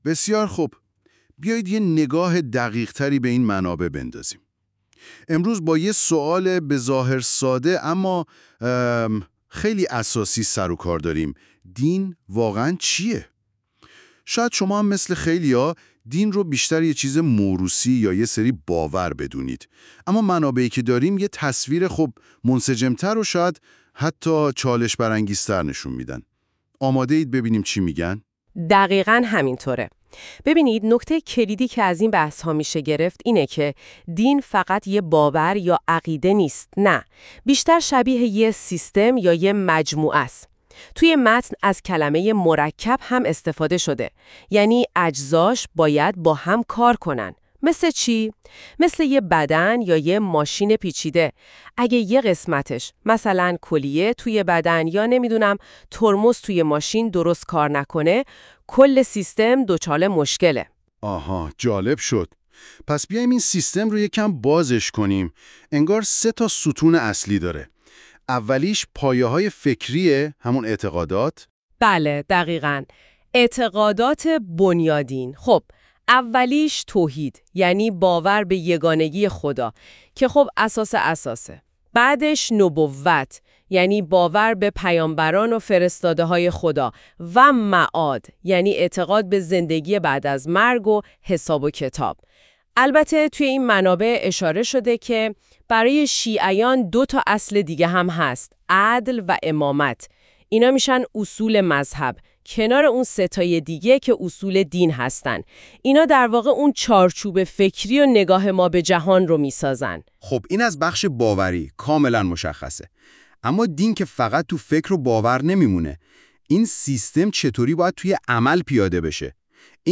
[با توجه به اینکه پادکست توسط هوش مصنوعی تولید می شود، ممکن است برخی از کلمات اشتباه تلفظ شود؛ همچنین ممکن است برخی مباحث حلقه در پادکست ذکر نشده و یا مطالبی خارج از حلقه در آن آورده شده باشد]